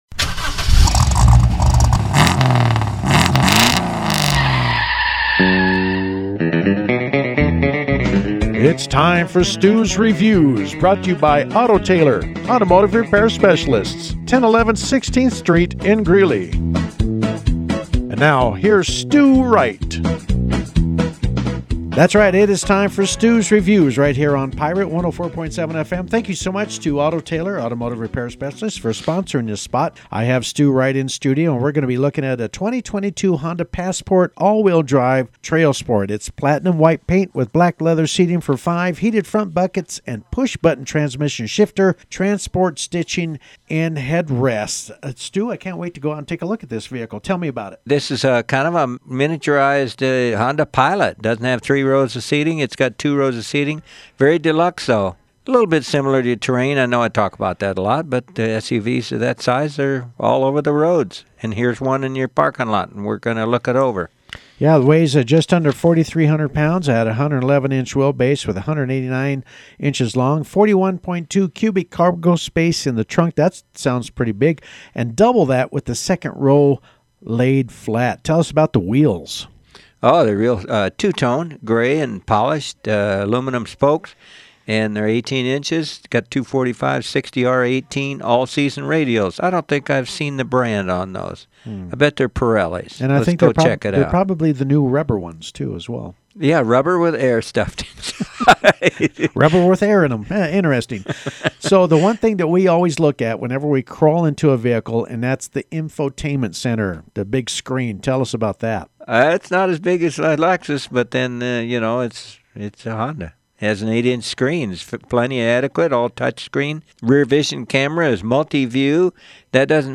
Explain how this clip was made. The new Honda Passport was reviewed at Pirate Studios (104.7FM)